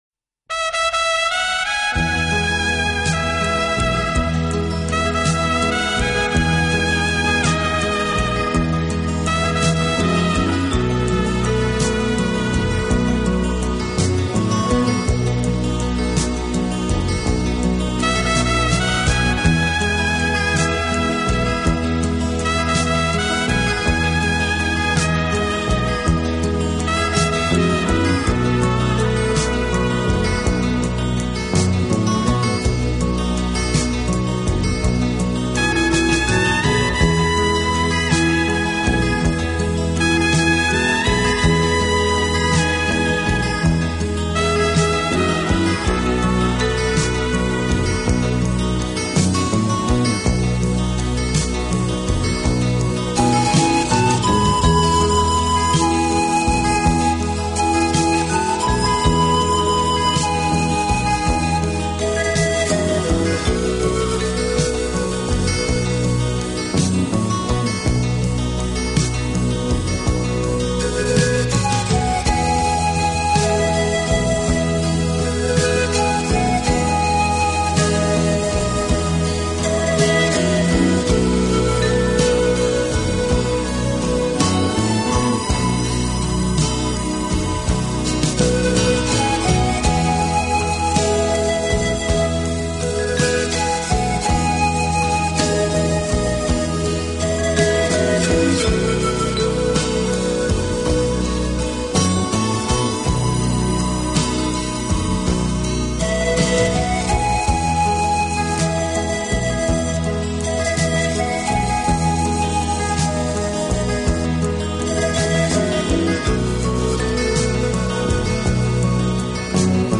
轻音乐作品的旋律优美动听、清晰流畅，节奏鲜明轻快，音色丰富多彩，深受